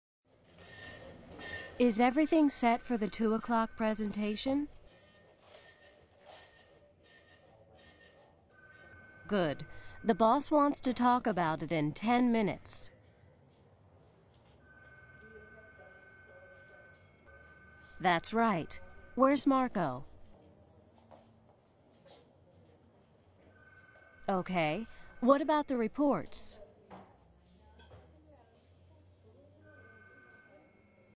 Listen to these people talking about the preparation for a presentation and check the words and expressions you know.